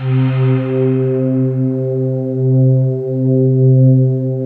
Index of /90_sSampleCDs/USB Soundscan vol.28 - Choir Acoustic & Synth [AKAI] 1CD/Partition C/08-FANTASY